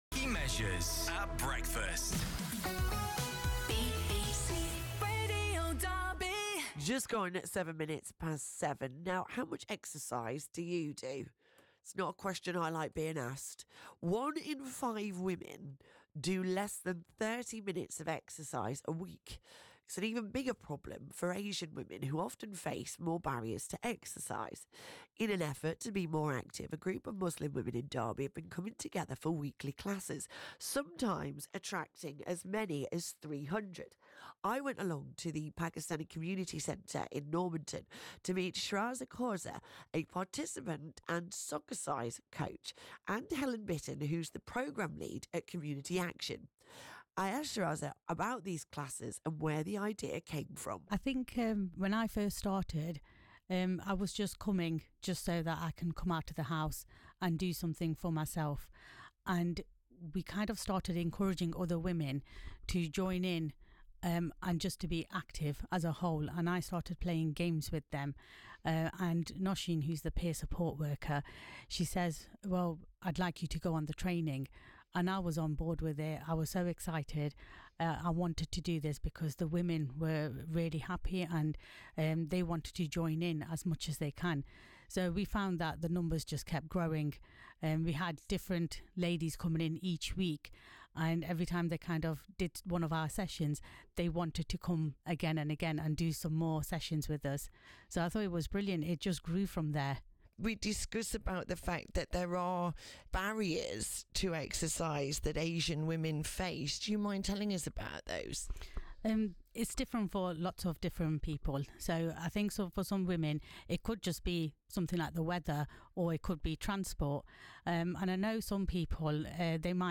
We have compiled the recordings so that you can listen to them all together: DE23 Active on BBC Radio Derby 23/9/25 Or you can listen on BBC Sounds